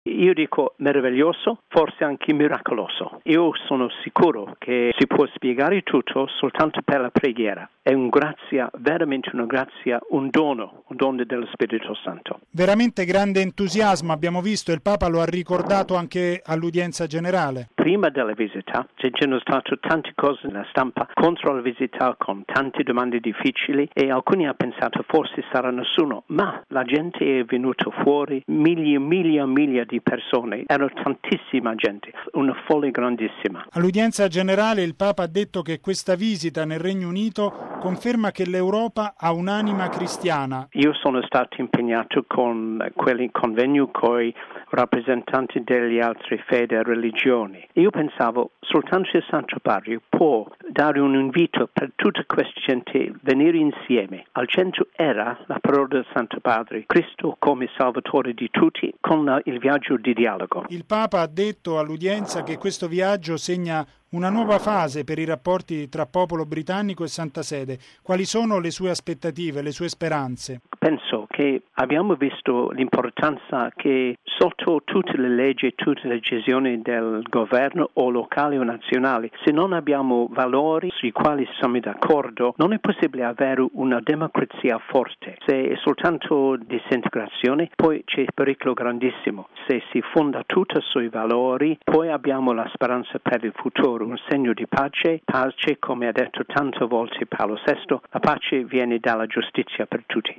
Il Papa all’udienza generale: la visita nel Regno Unito conferma che l’Europa ha un’anima cristiana. Il commento dell'arcivescovo di Liverpool